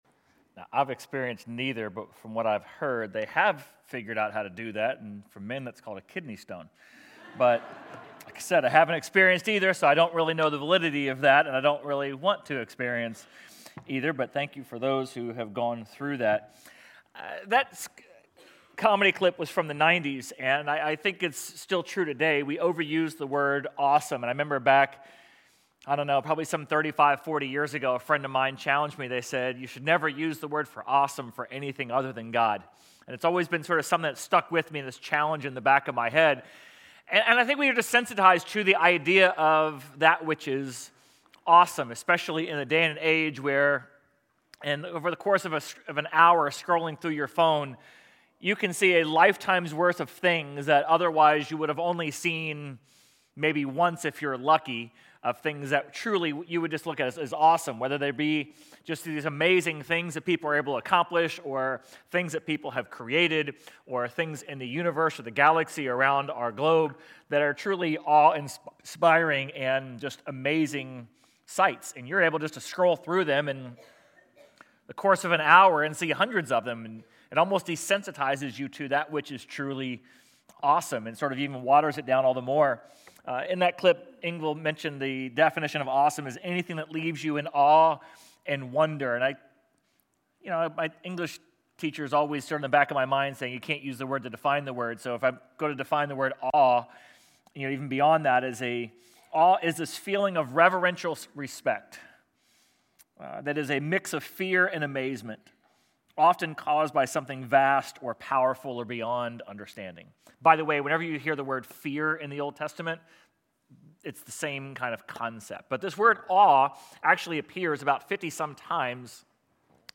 Sermon_3.8.26.mp3